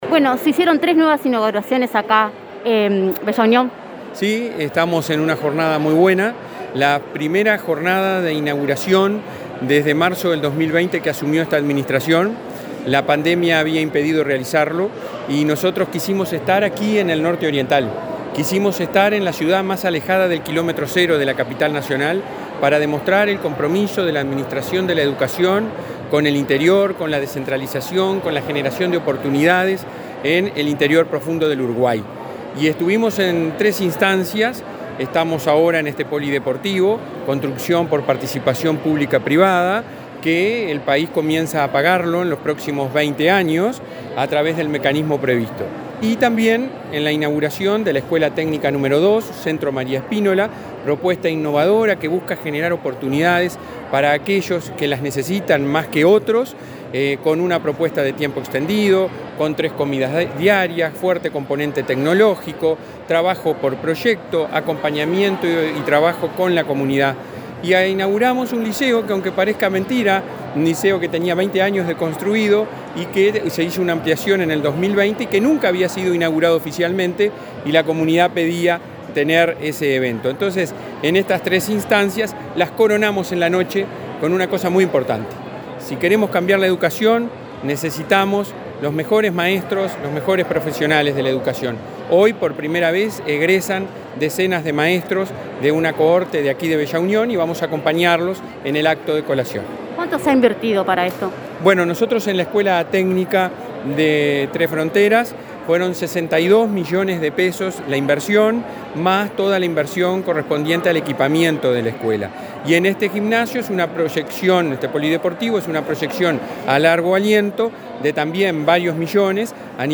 Declaraciones del presidente de la ANEP, Robert Silva
Declaraciones del presidente de la ANEP, Robert Silva 12/11/2021 Compartir Facebook X Copiar enlace WhatsApp LinkedIn La ANEP inauguró, este 12 de noviembre, en Bella Unión un liceo, una escuela técnica y un gimnasio polideportivo. Tras el evento, Silva efectuó declaraciones a Comunicación Presidencial.